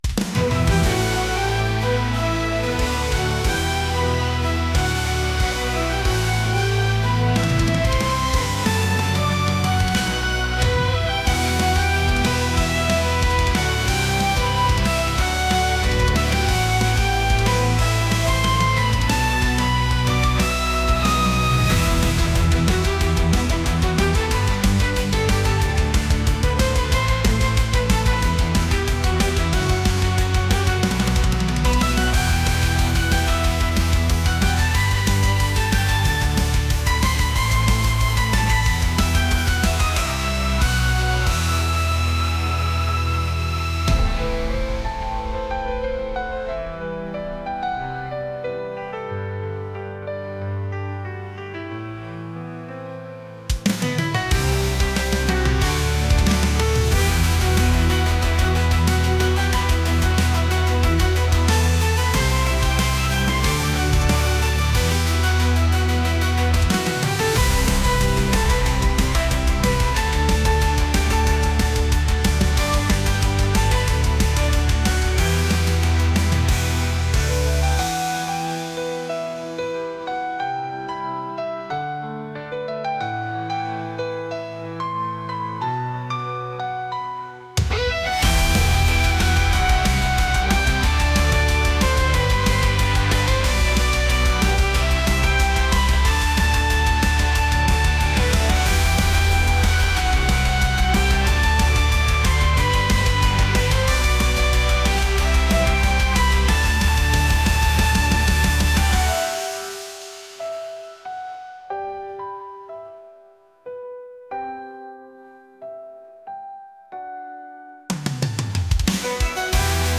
rock | metal